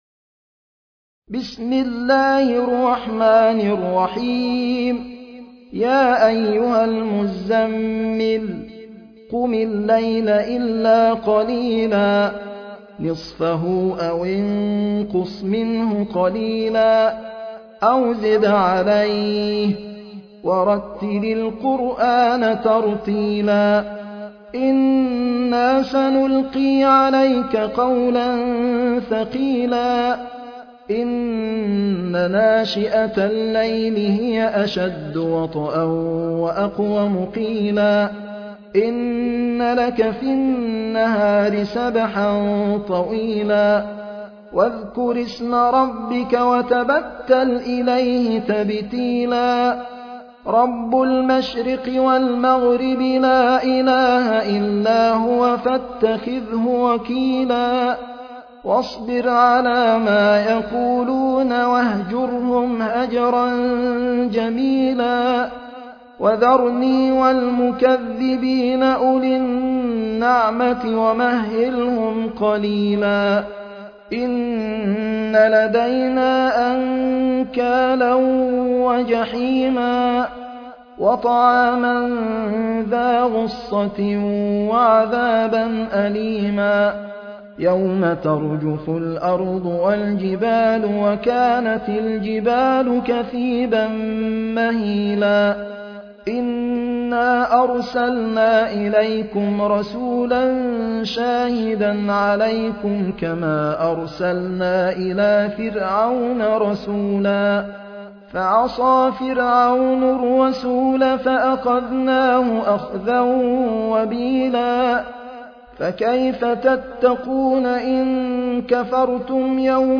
المصحف المرتل - حفص عن عاصم - المزمل